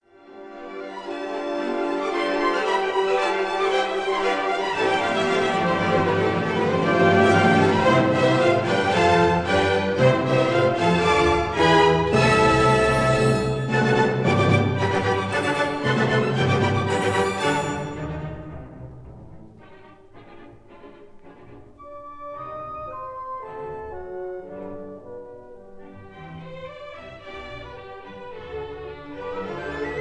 Allegro giocoso